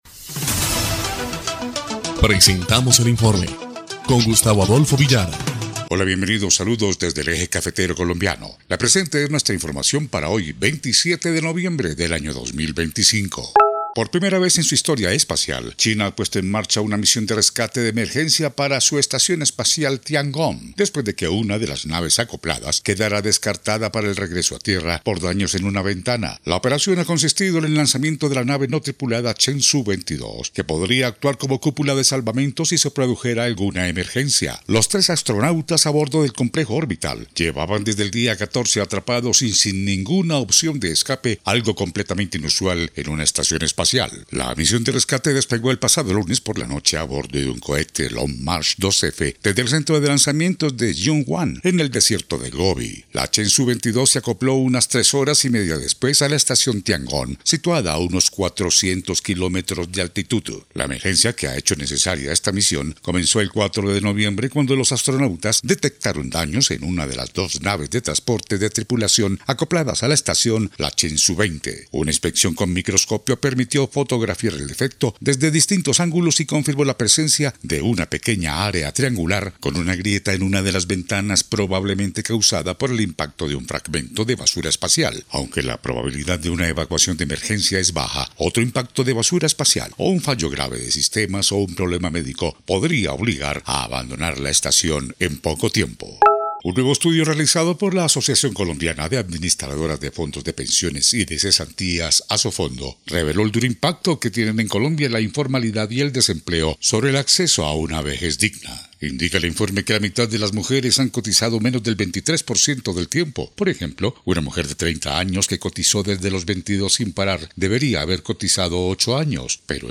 EL INFORME 2° Clip de Noticias del 27 de noviembre de 2025